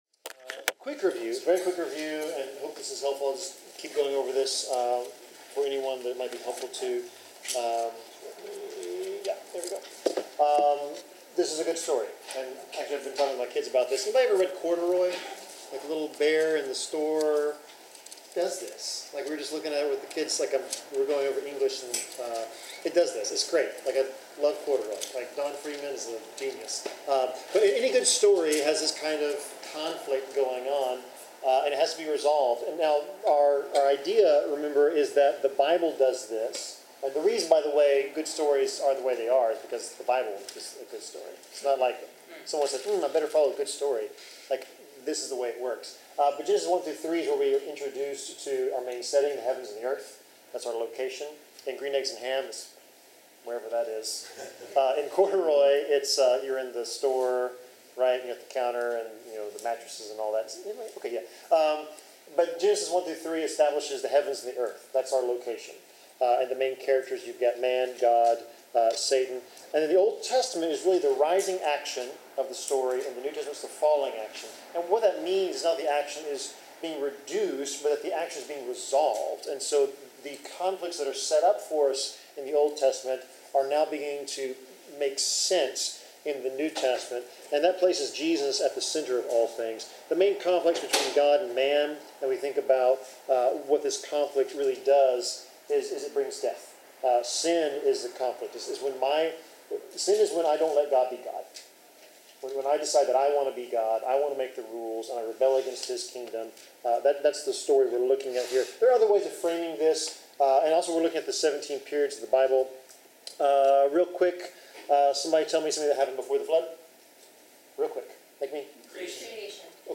Bible class: Deuteronomy 25-26
Passage: Deuteronomy 25:5-26:19 Service Type: Bible Class